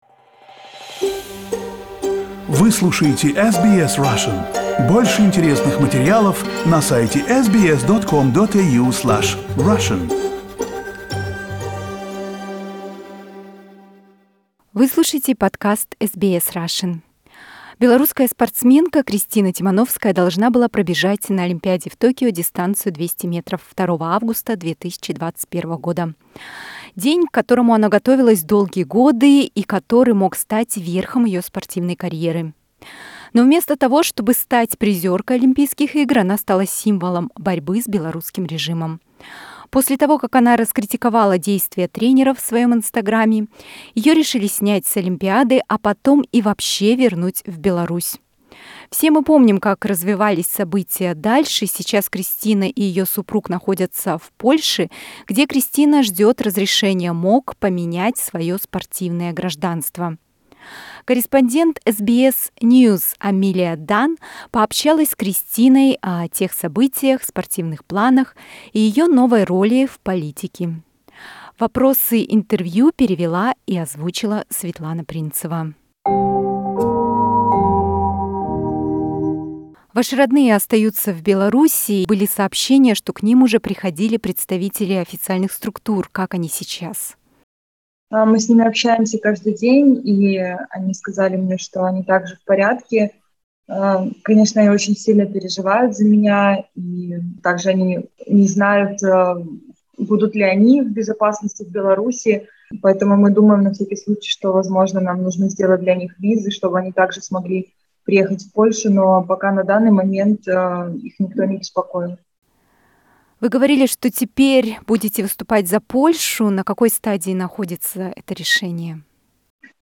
Kristina Tsimanovskaya's first interview with Australian media